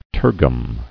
[ter·gum]